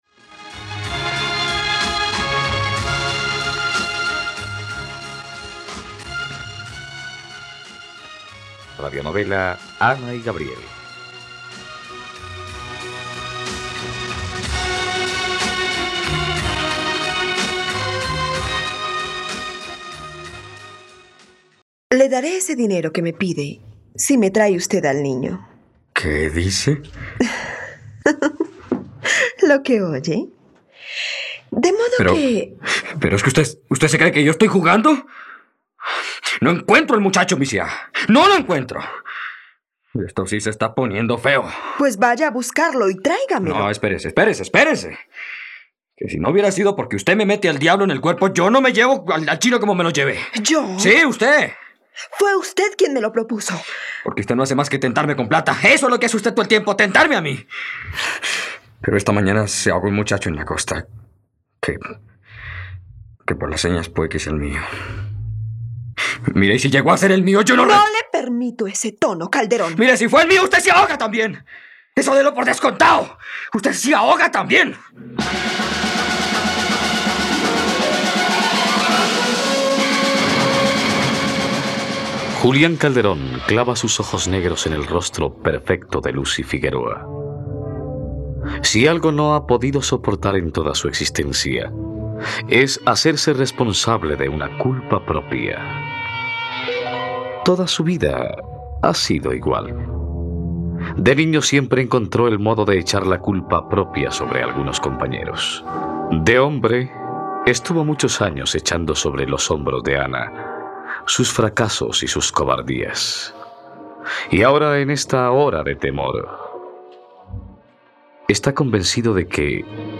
Ana y Gabriel - Radionovela, capítulo 90 | RTVCPlay